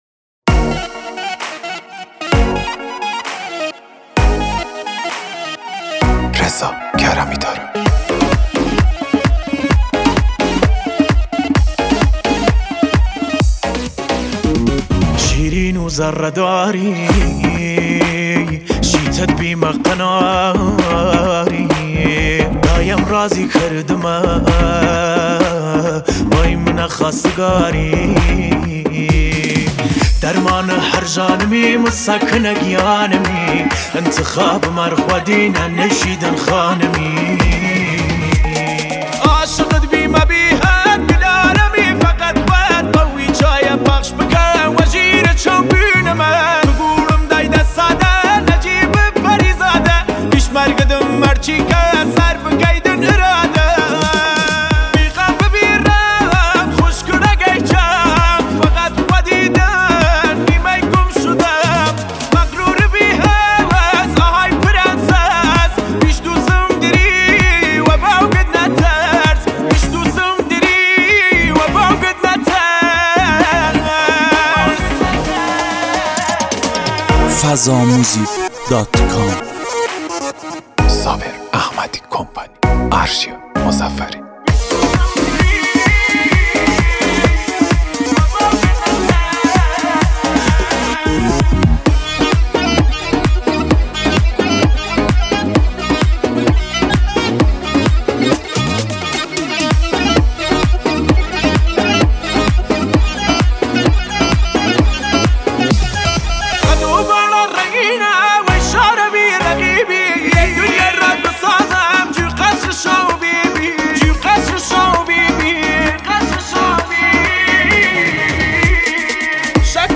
ترانه کردی